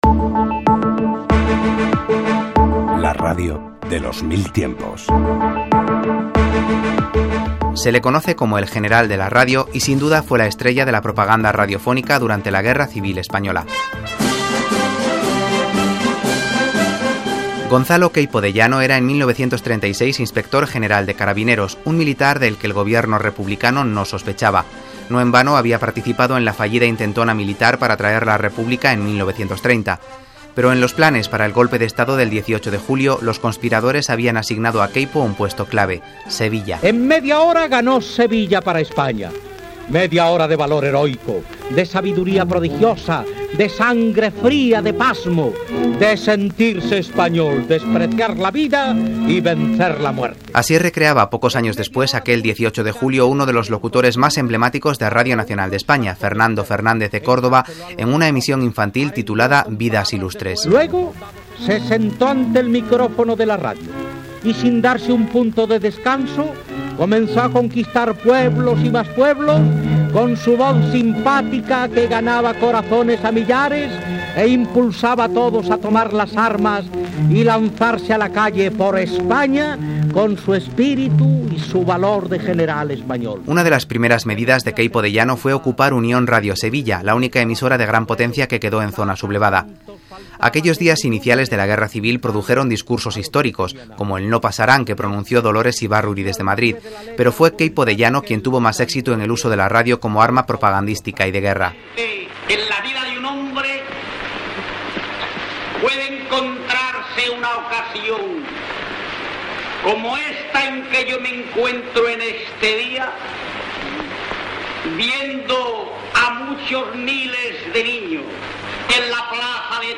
En sus discursos radiofónicos no dejaba de lado las bromas, la ironía, un tono popular, incluso chabacano.
Este reportaje de RNE repasa su figura y los detalles de esta innovadora estrategia e incorpora una de las pocas grabaciones que se conservan de los programas de radio de Queipo de Llano.